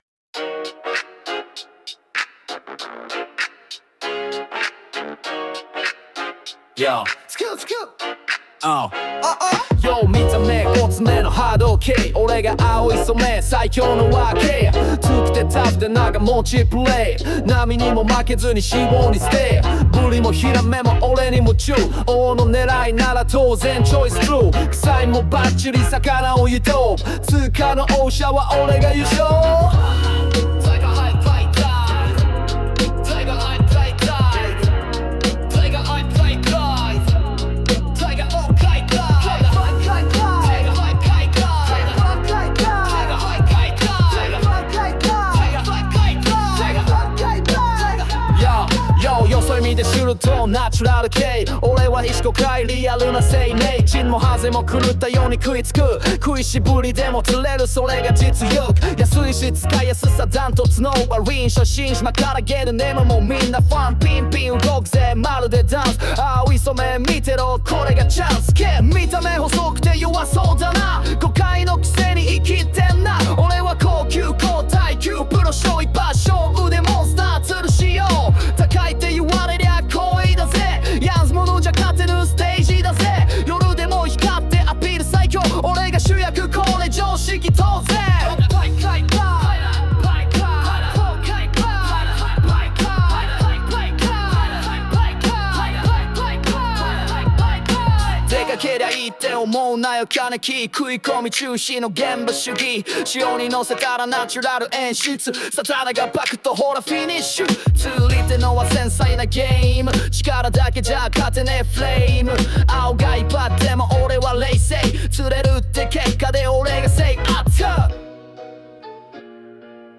🎤青イソメ vs 石ゴカイ　ラップバトル！